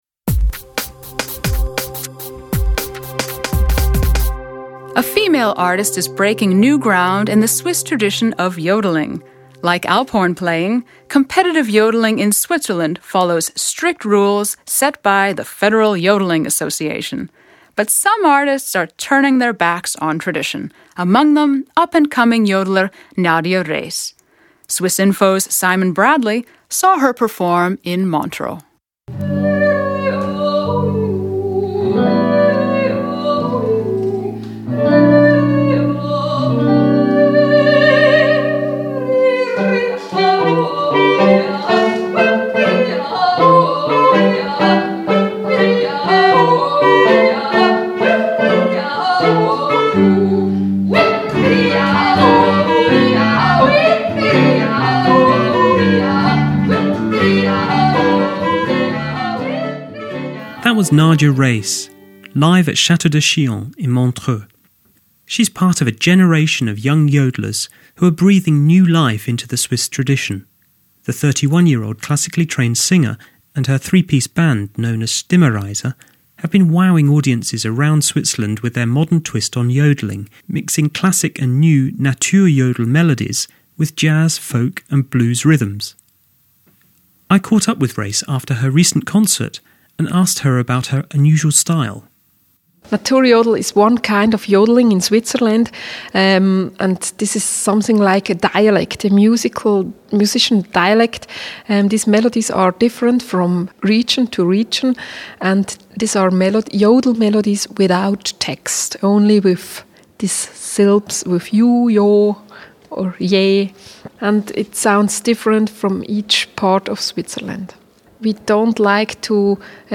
Modern-day yodeller